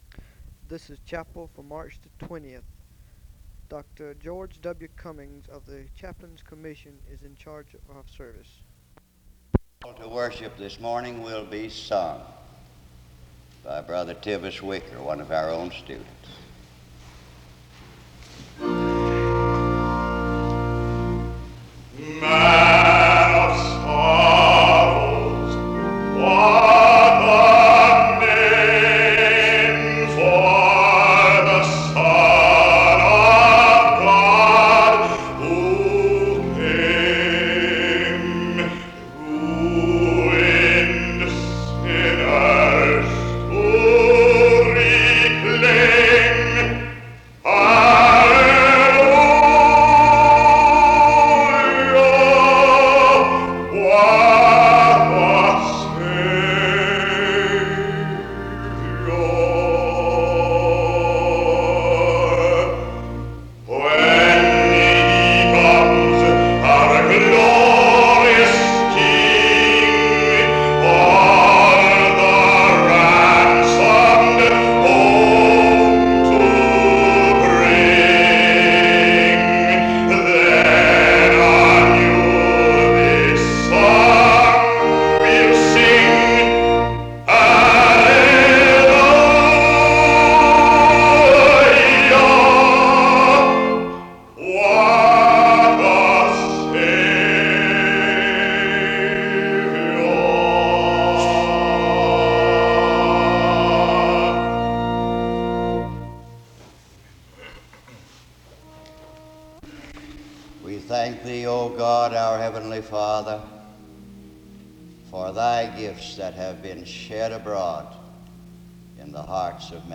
This service was a chaplain commissioning service.
There is a closing prayer from 19:16-20:03.